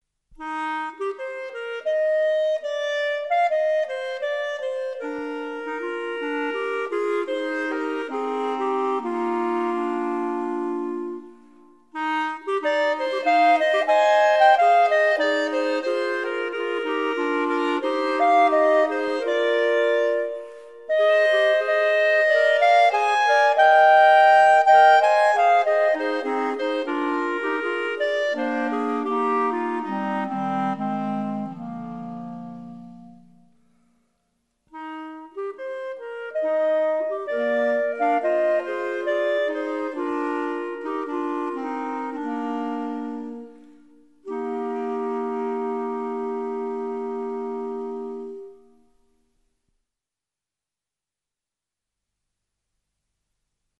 Gattung: Volksmusikstücke
Besetzung: Volksmusik/Volkstümlich Weisenbläser